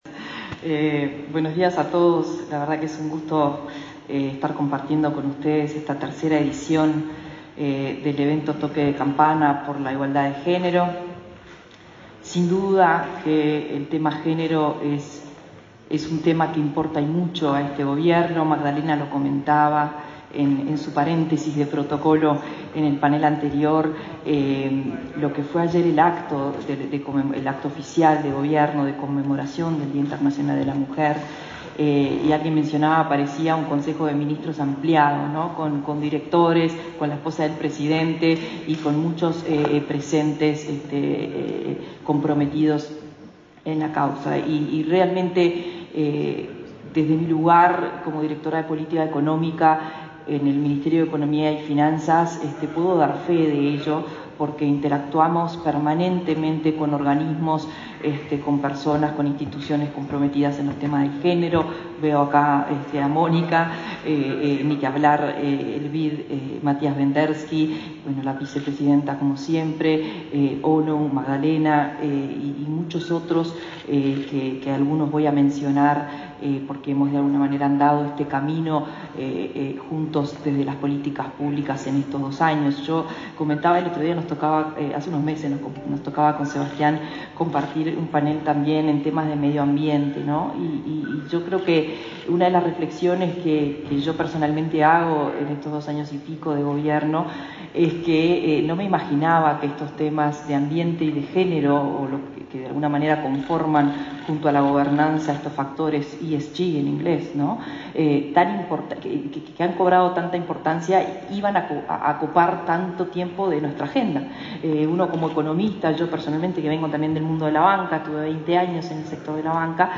Disertación de Marcela Bensión, del Ministerio de Economía
Disertación de Marcela Bensión, del Ministerio de Economía 09/03/2022 Compartir Facebook X Copiar enlace WhatsApp LinkedIn La directora de Política Económica del Ministerio de Economía, Marcela Bensión, intervino, este martes 9, en un panel sobre instrumentos innovadores que impulsan la igualdad de género, realizado en la Bolsa de Valores de Montevideo.